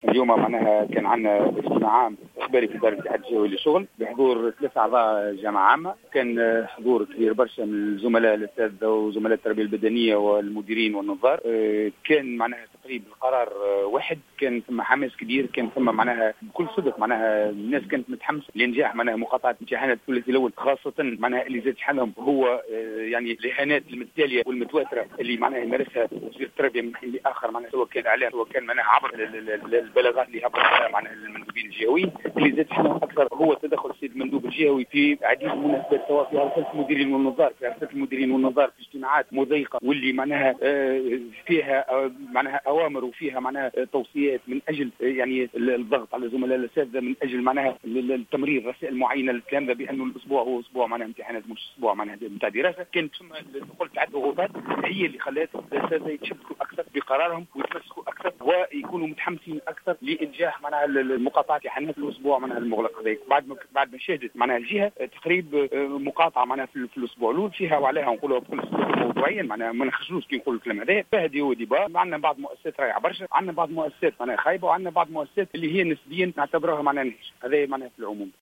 في تصريح للجوهرة اف ام